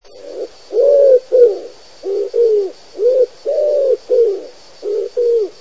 Pigeon ramier
Columba palumbus
ramier.mp3